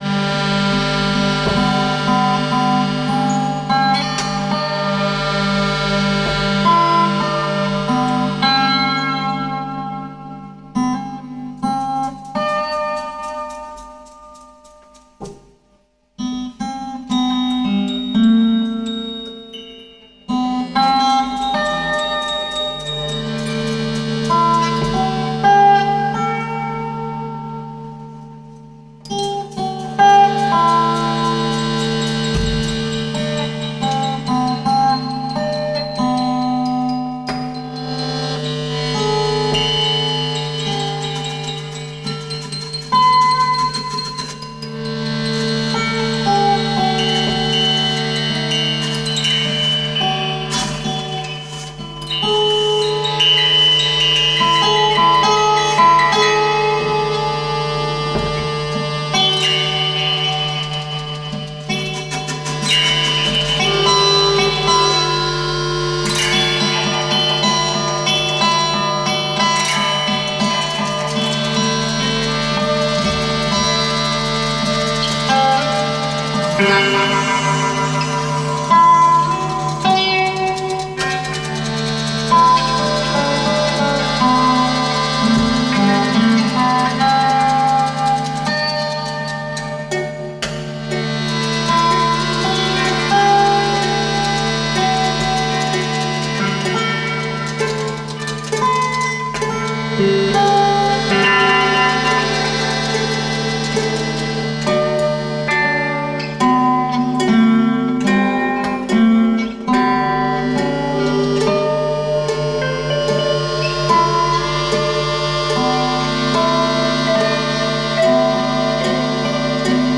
a collective of five musicians